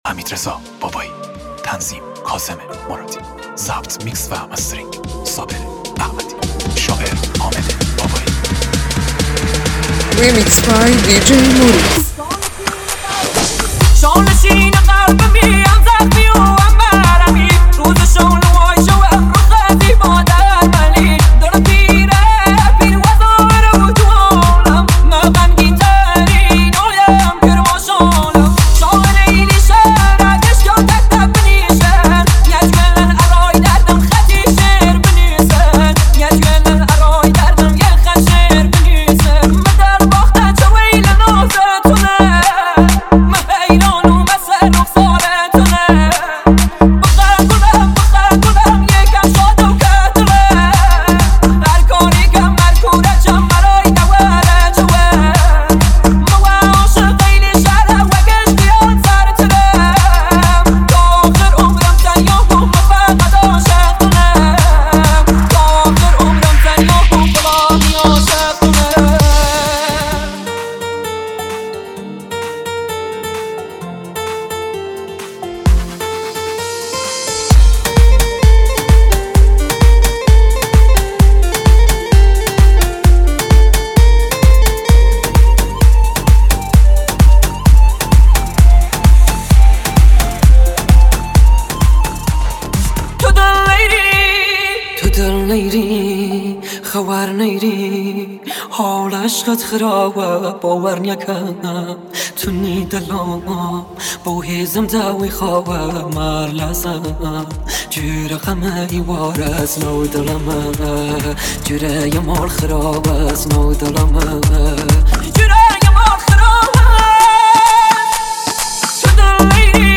آهنگ محلی کردی
ریمیکس تند بیس دار